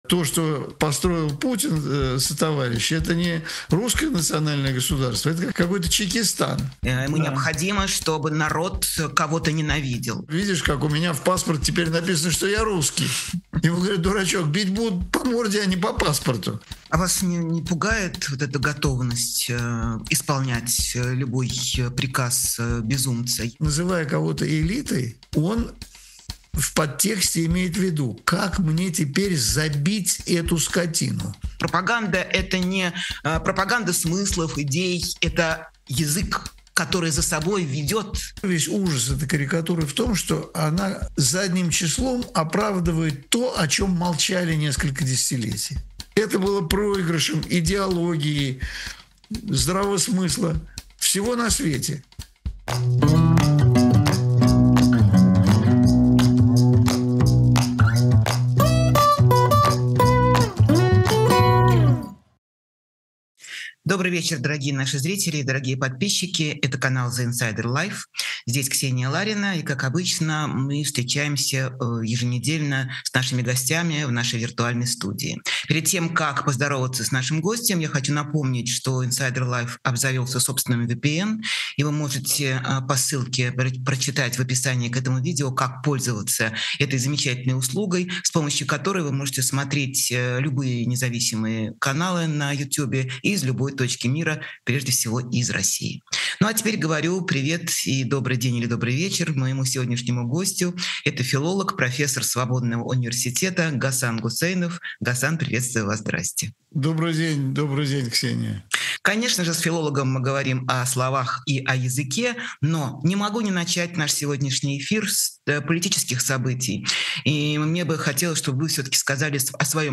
Эфир ведёт Ксения Ларина
Гость — филолог, профессор Свободного университета Гасан Гусейнов.